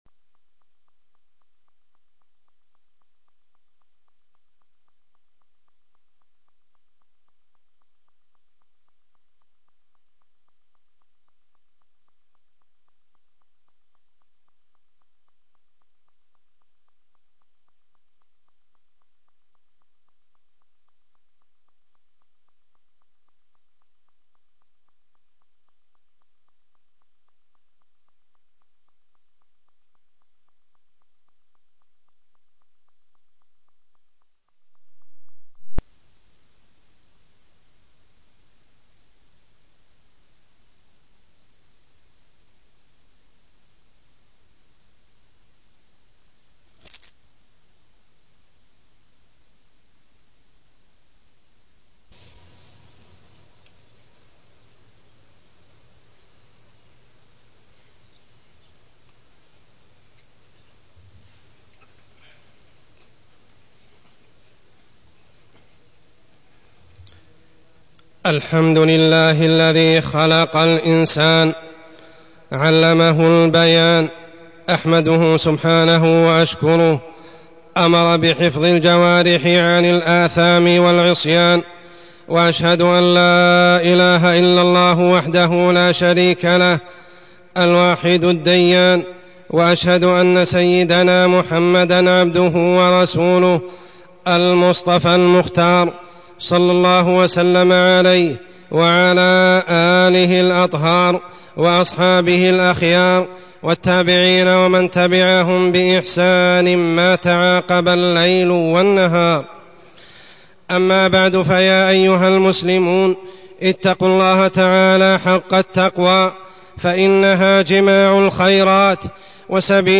تاريخ النشر ٤ ذو القعدة ١٤٢٢ هـ المكان: المسجد الحرام الشيخ: عمر السبيل عمر السبيل حفظ اللسان